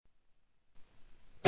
CLAVECIN ITALIEN
Le clavier est transpositeur, avec des marches plaquées d'os; les feintes sont en buis et ébène.
Il mesure 1950 mm de long et a deux jeux de 8 pieds.
Vous pouvez écouter cet instrument dans un extrait de la Sonate n° 3 de Cimarosa en cliquant ici.